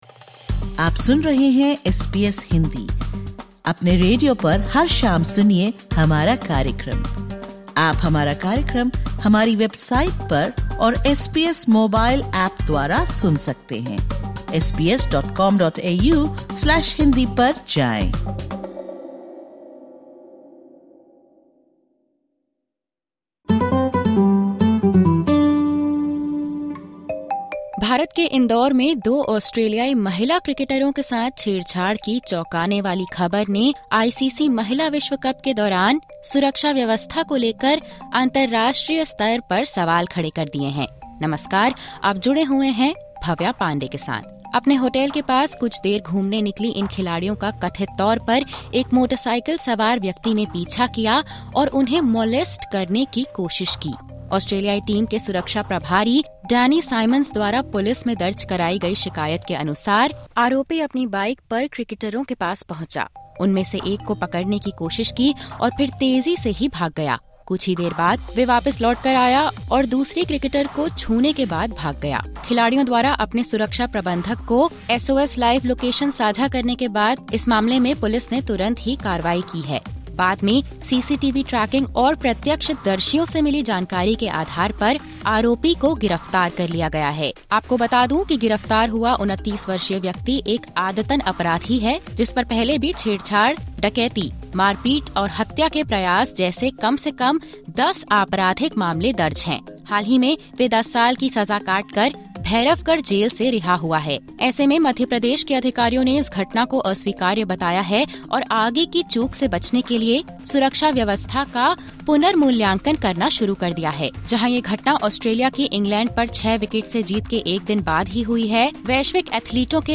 ( Disclaimer: The views/opinions expressed in this interview are the personal views of the individual.